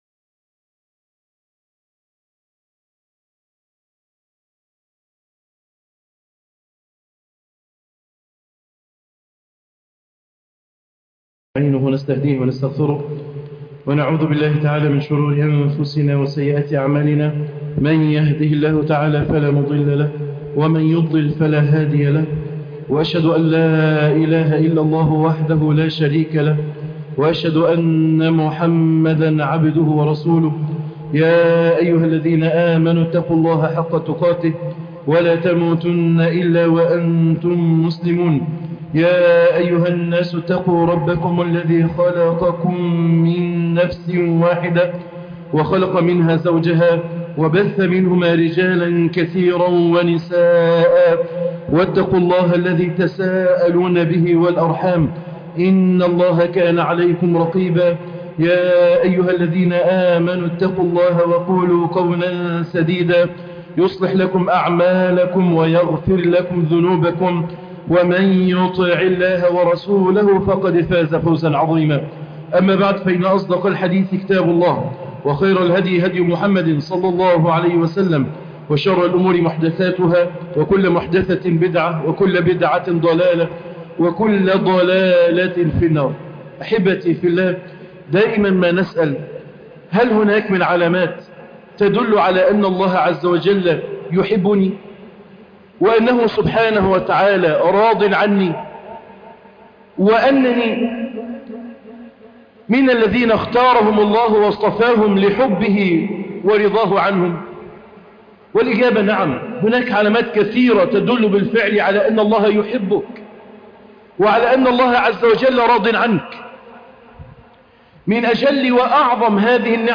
علامات محبة الله تعالى للعبد في الدنيا - خطبة الجمعة